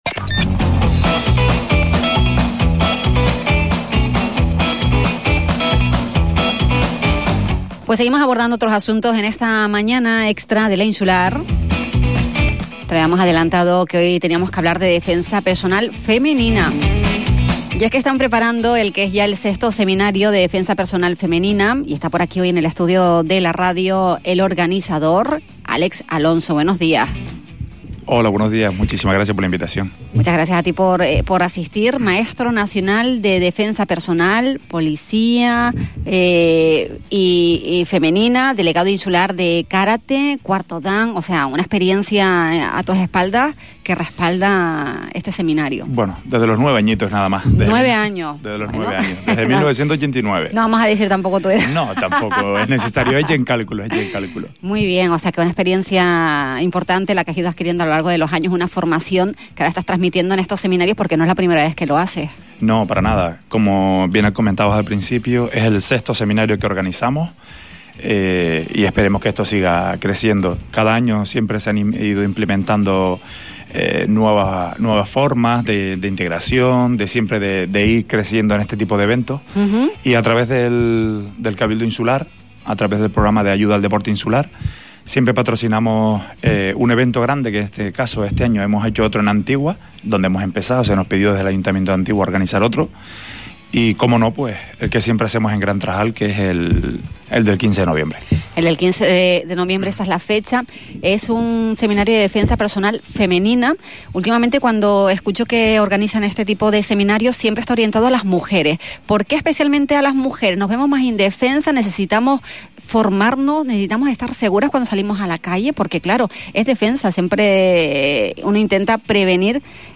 En una entrevista en La Mañana Xtra de Radio Insular